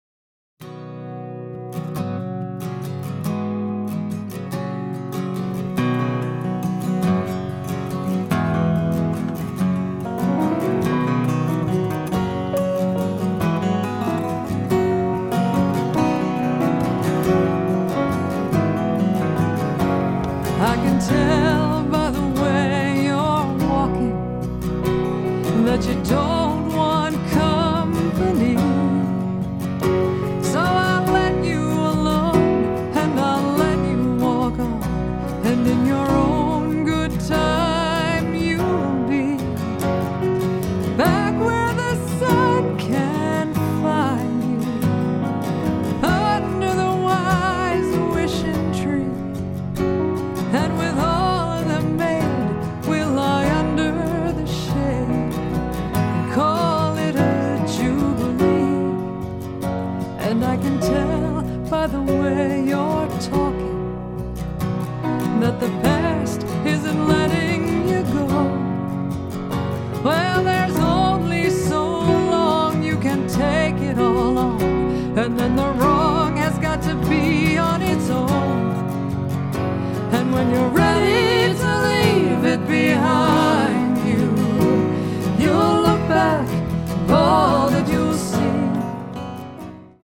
guitar and vocals
banjo and vocals
guitar and mandolin